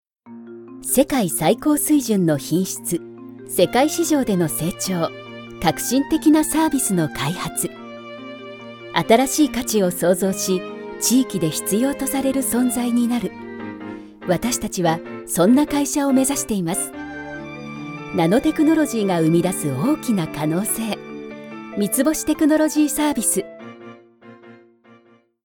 Vidéos d'entreprise
Sa voix douce, claire et attachante est très polyvalente, ce qui en fait le choix incontournable de nombreuses grandes entreprises et organismes gouvernementaux.